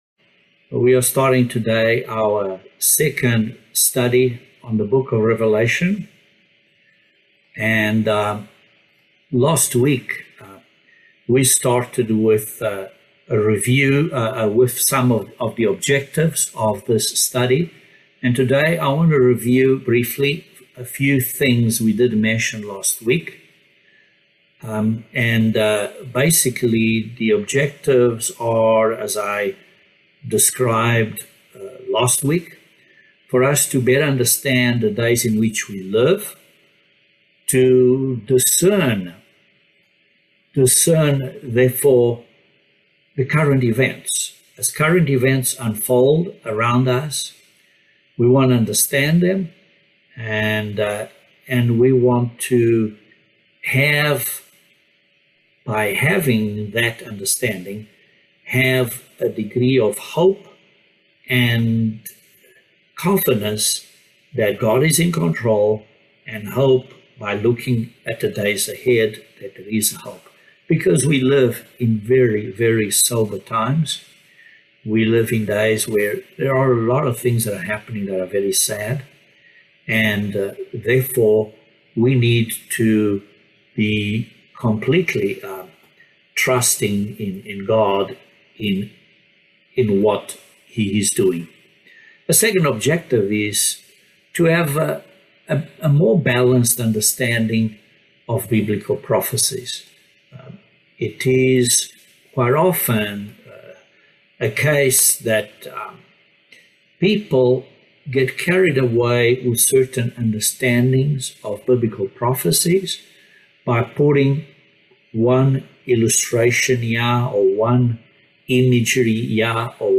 Bible Study no 2 of Revelation